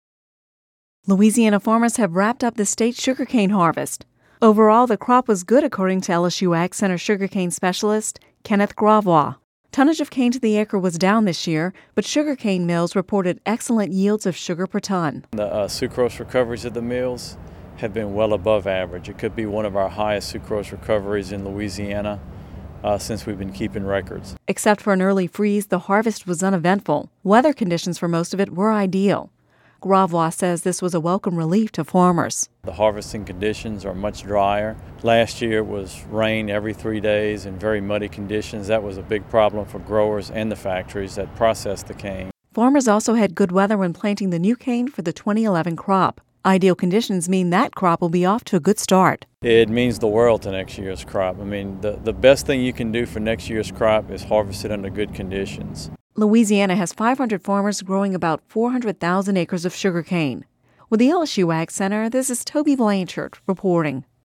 (Radio News 01/03/11) Louisiana farmers have wrapped up the state’s sugarcane harvest.